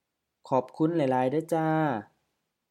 BCF06 Expressing thanks (2) — Dialogue A
จ้า ja: HF ค่ะ female politeness particle